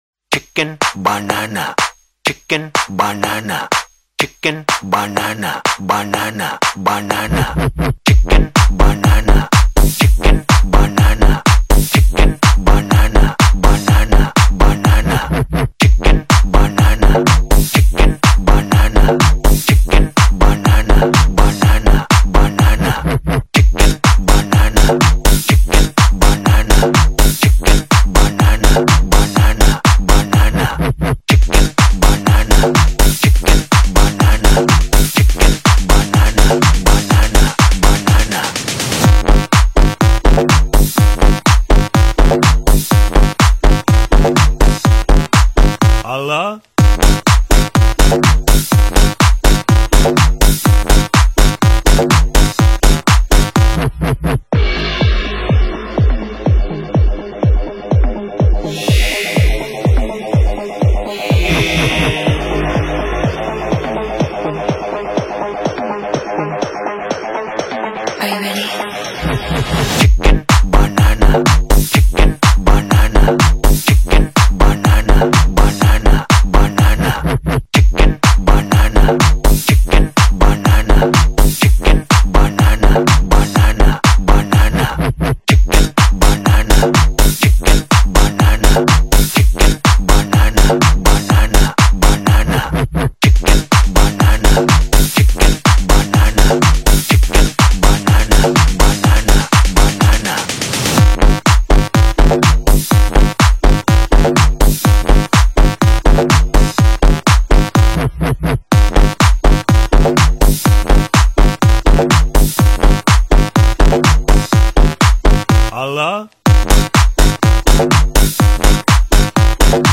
This track has become a crazy dance-pop anthem